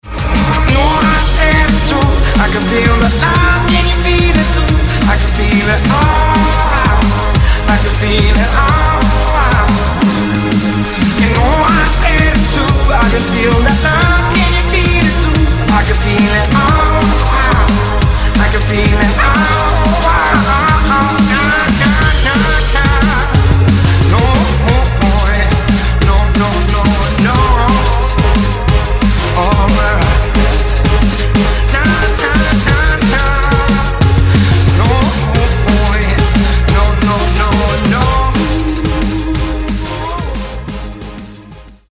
мужской вокал
dance
быстрые
drum n bass